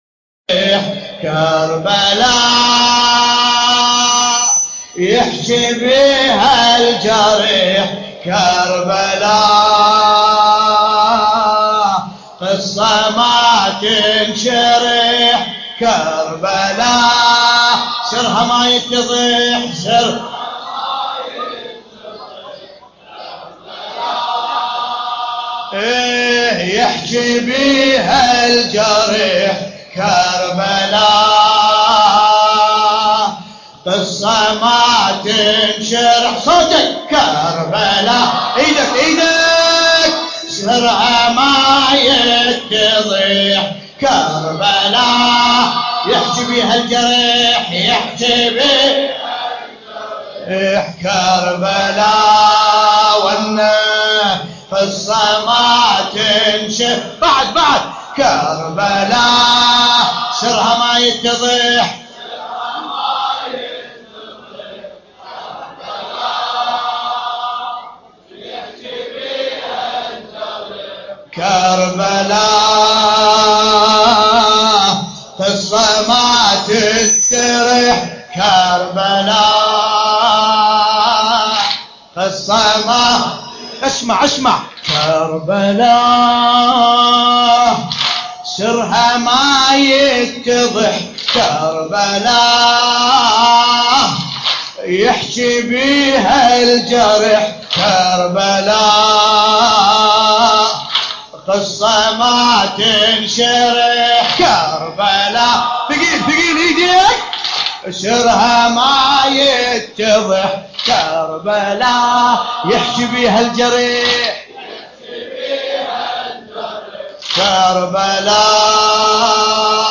القارئ: باسم الكربلائي التاريخ: الليلة الثانية من شهر محرم الحرام 1434 هـ - الكويت .